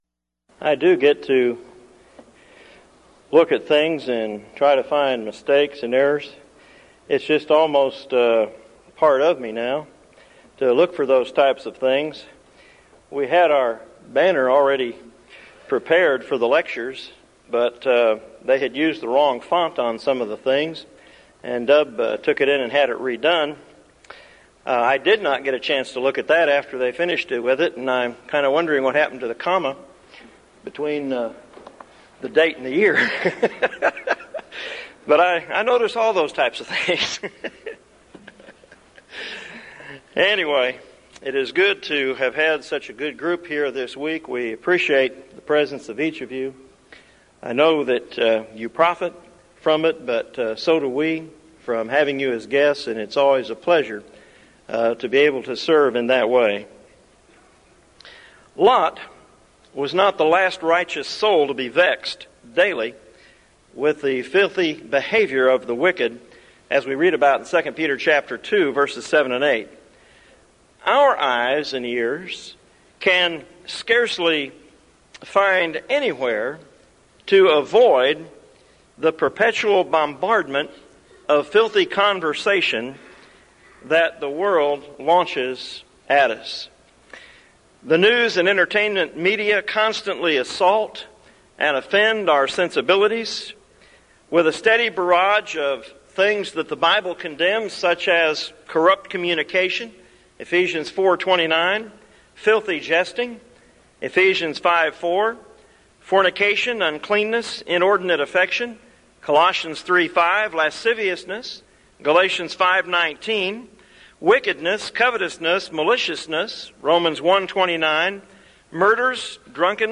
Event: 1998 Denton Lectures
lecture